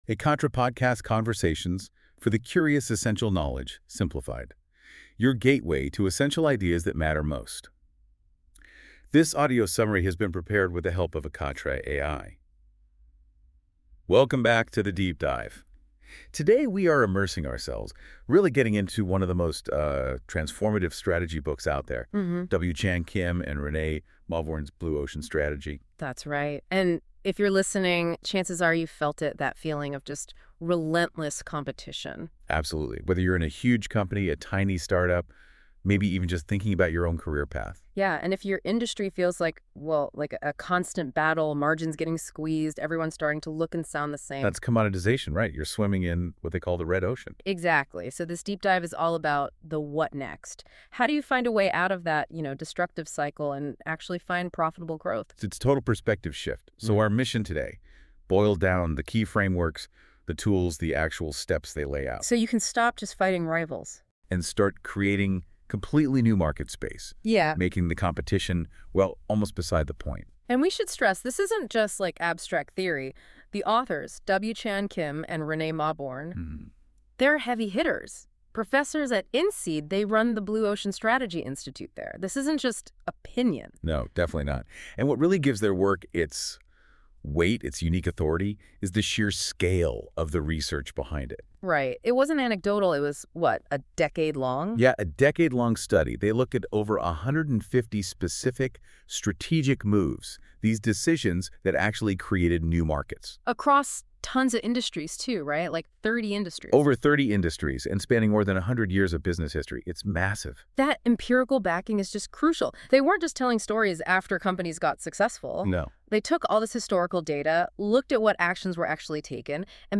Ekatra audio summary – English